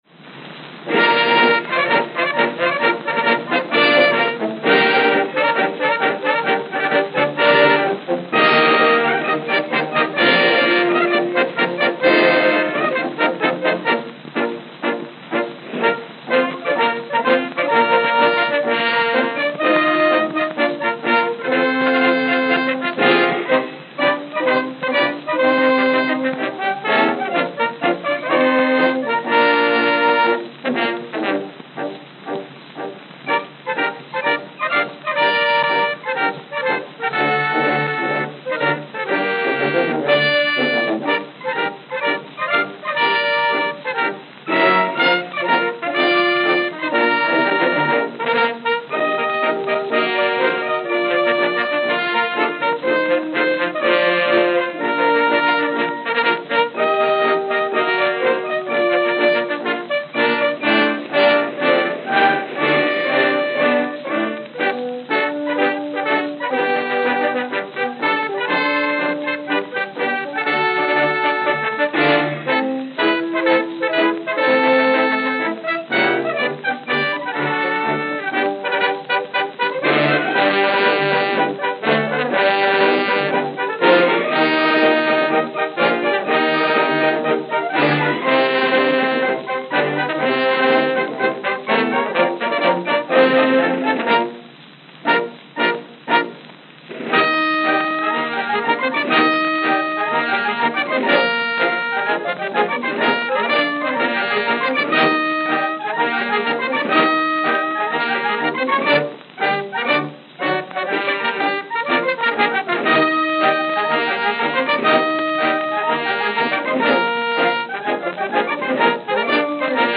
Edison Diamond Discs
Note: Worn.